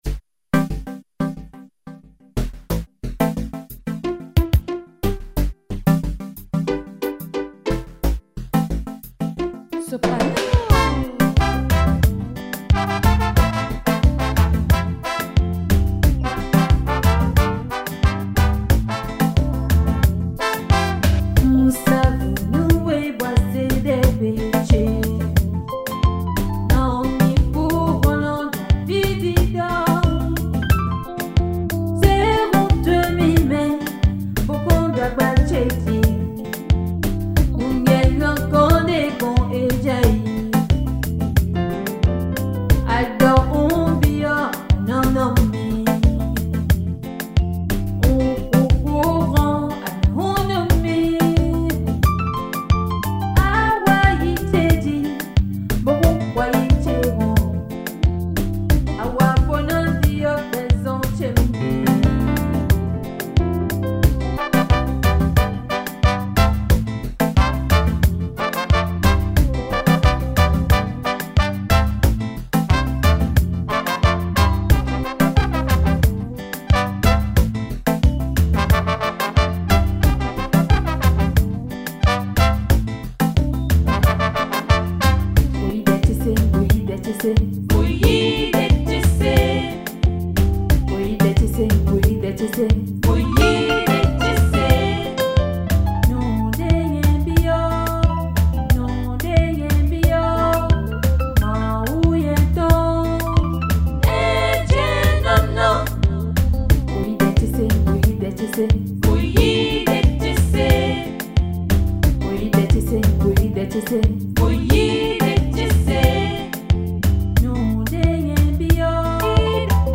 Chantre